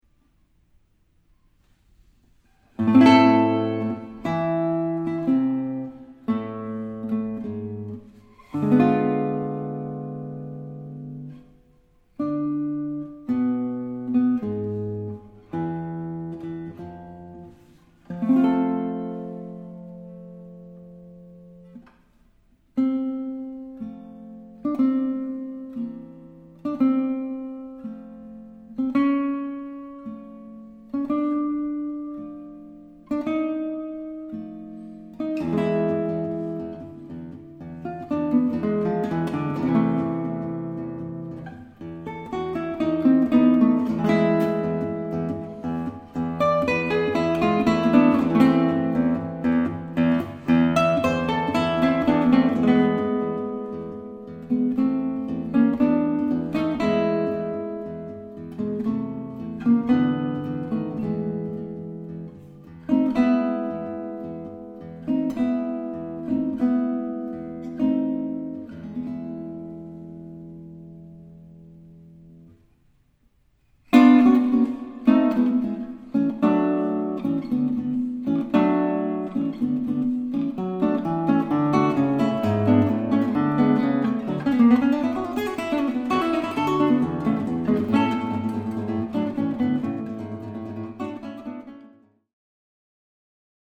#253, Classical guitar played by a customer who sent me this sound file.
253-Guitar-Sample.mp3